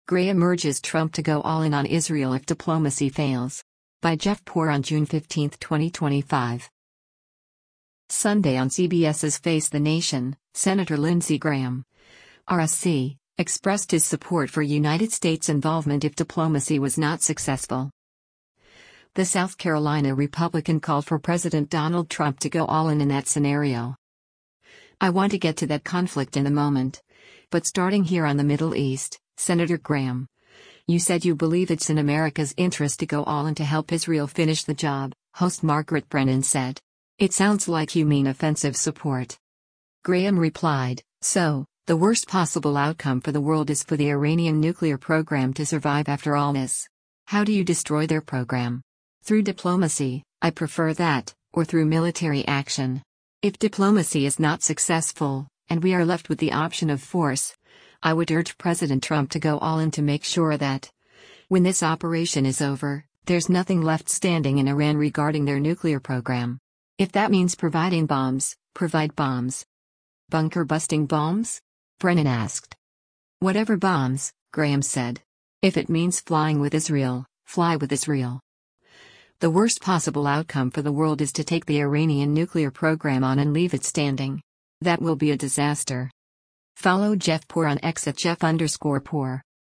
Sunday on CBS’s “Face the Nation,” Sen. Lindsey Graham (R-SC) expressed his support for United States involvement if “diplomacy” was not successful.